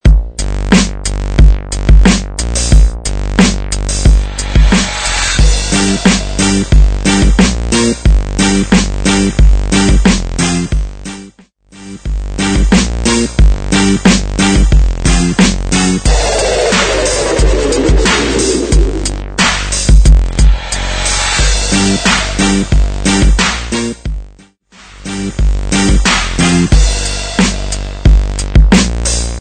Music Bed